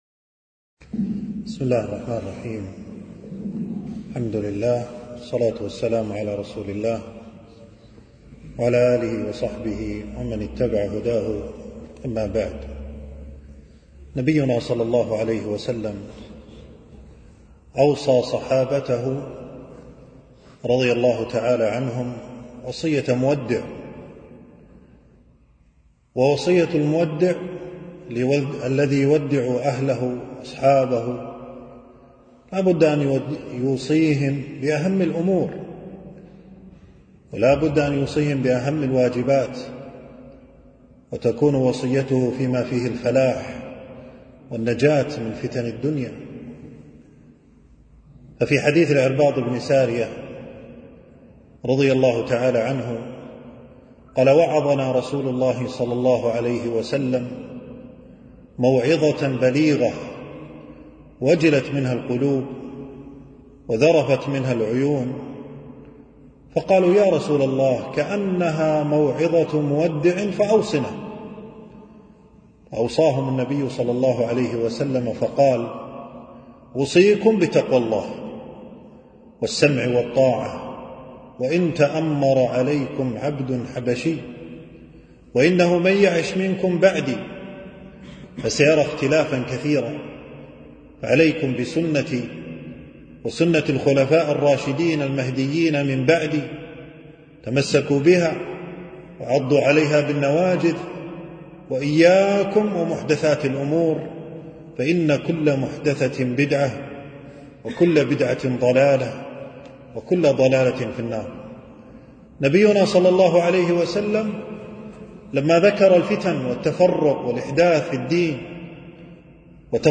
تنزيل تنزيل التفريغ محاضرة بعنوان: التمسك بالسنة والحذر من البدعة.
في مسجد هشام العصفور بمدينة المطلاع.